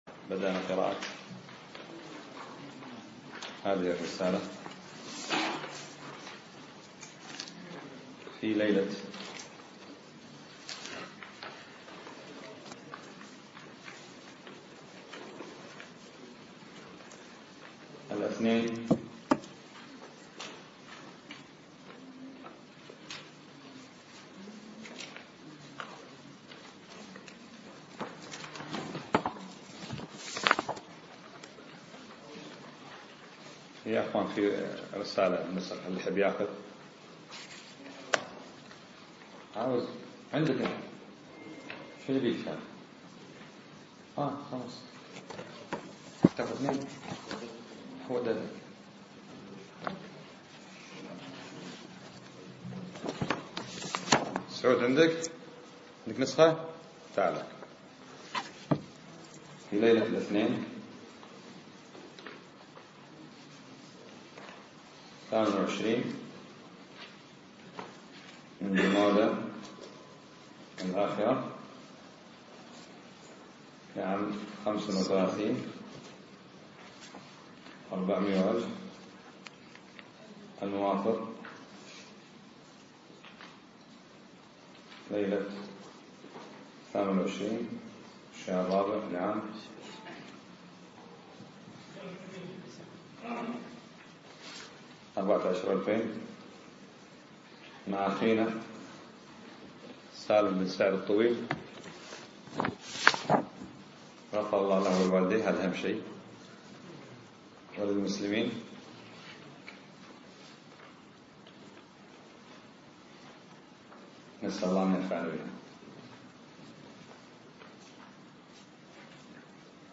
الدرس 1 - شرح دروس في الأسماء والصفات للشيخ محمد الأمين الشنقيطي رحمه الله